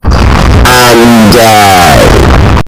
Efek Suara Anjay Gede
Kategori: Suara viral
Keterangan: Sound Effects/ Efek suara "Anjay Gede" menjadi viral di Indonesia, sering digunakan dalam meme dan edit video untuk menambah keseruan konten di media sosial.